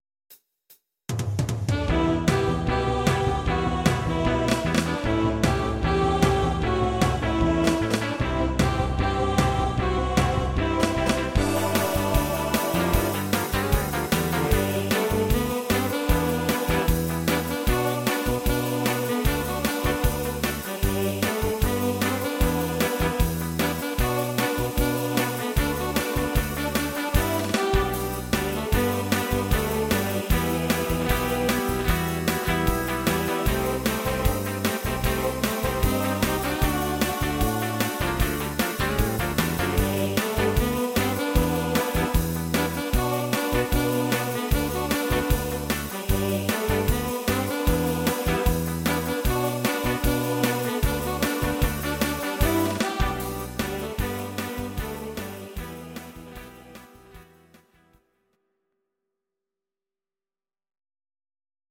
These are MP3 versions of our MIDI file catalogue.
Please note: no vocals and no karaoke included.
Your-Mix: Rock (2970)